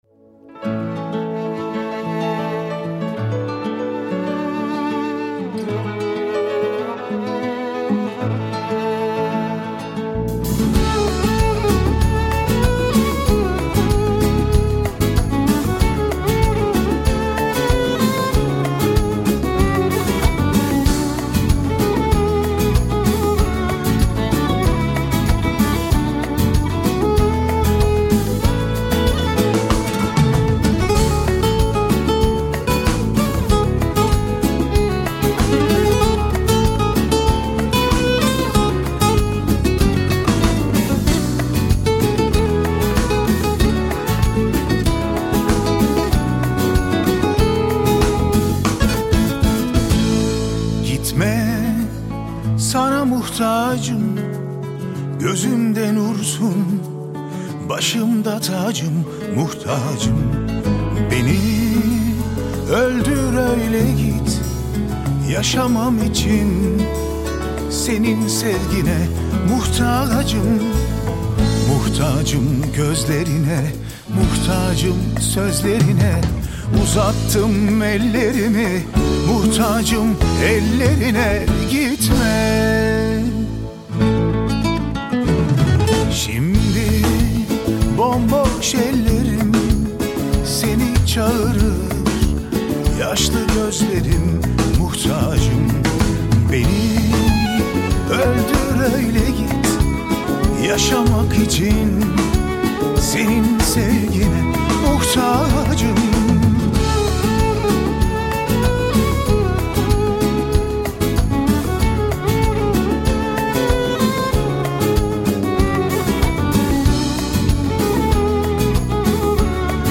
Genre: Ethnic / Folk - pop / Ethnic jazz